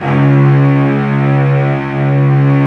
Index of /90_sSampleCDs/Best Service ProSamples vol.55 - Retro Sampler [AKAI] 1CD/Partition A/CELLO SECTIO